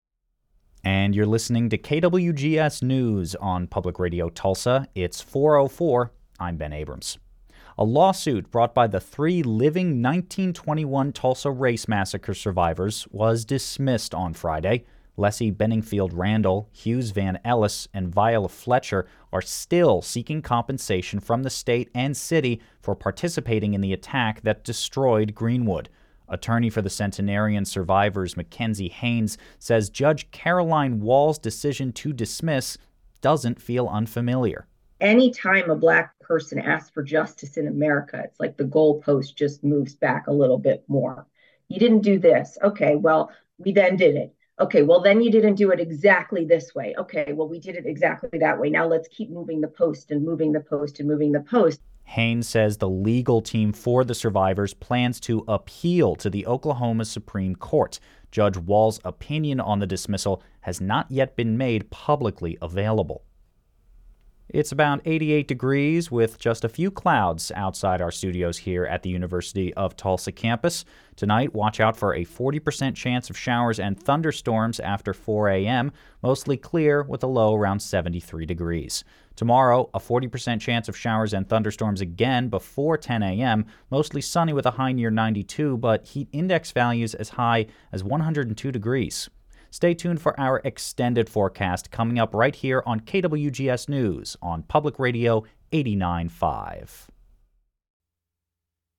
news-newscast-1604mp3.mp3